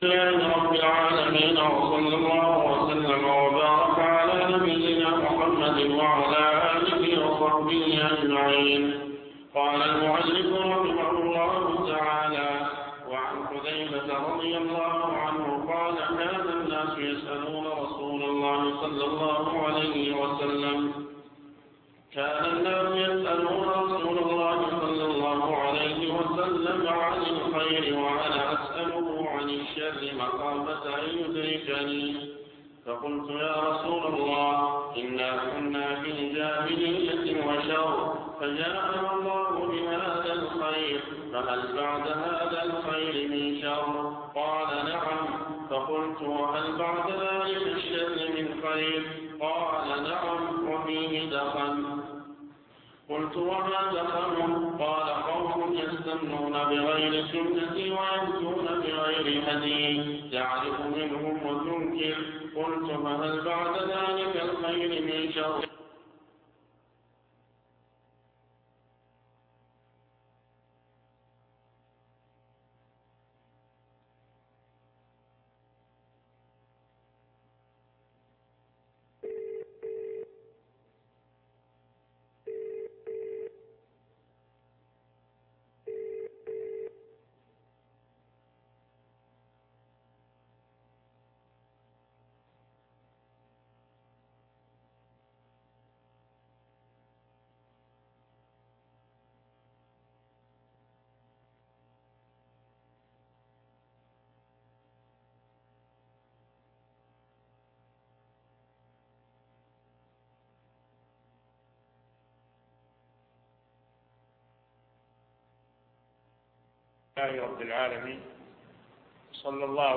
الدرس 5